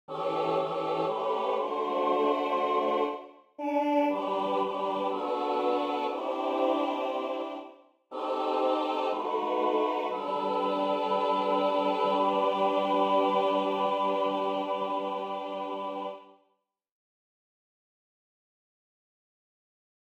How many parts: 4
Type: Barbershop
All Parts mix: